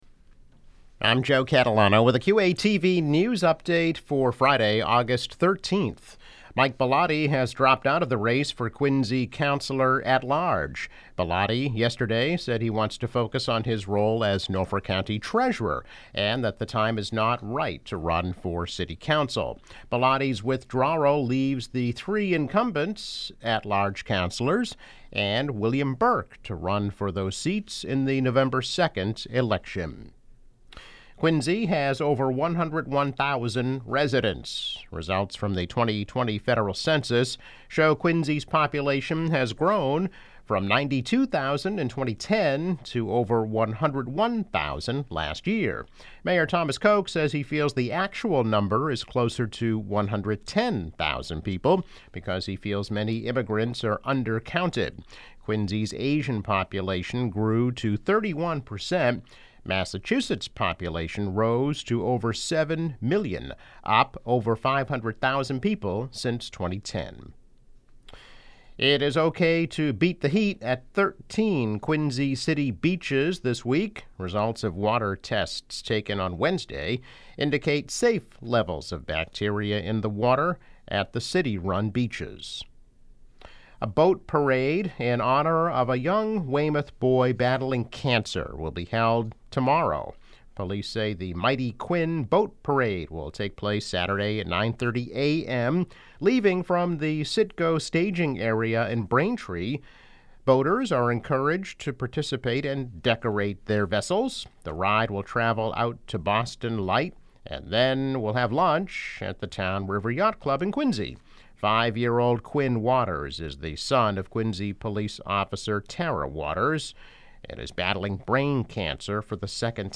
News Update - August 13 2021